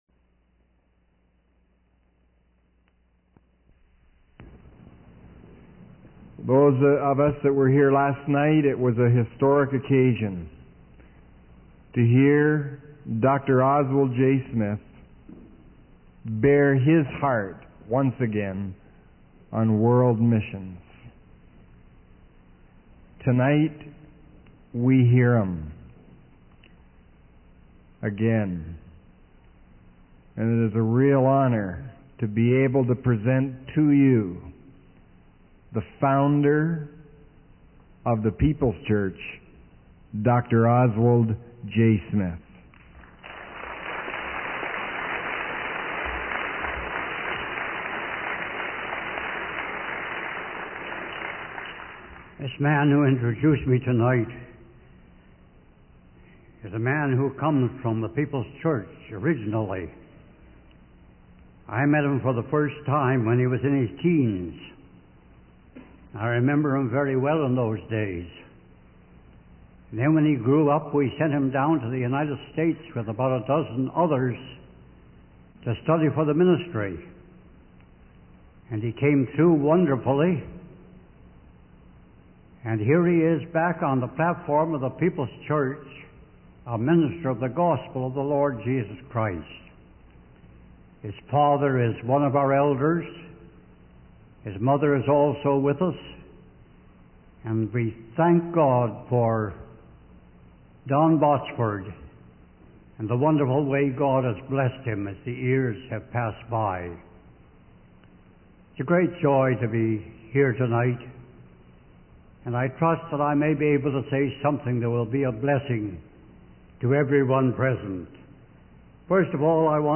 In this sermon, the speaker emphasizes the importance of sending laborers into the harvest fields of the world to spread the gospel. He shares a personal story about the impact of missionary work on his own life and poses the question of why anyone should hear the gospel twice before everyone has heard it once. The speaker urges the audience to take action by either going themselves or supporting missionaries financially.